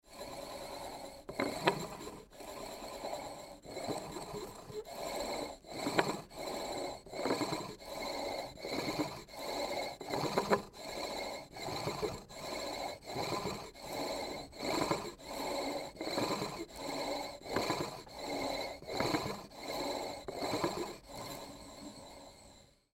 Stone age drill